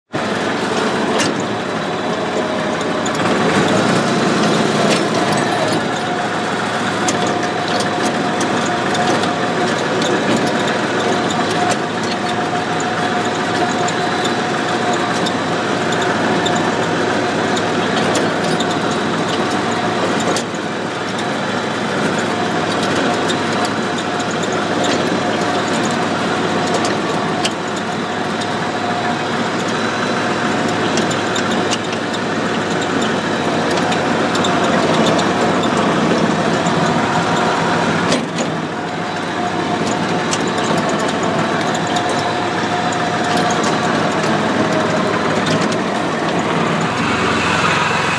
Rotovating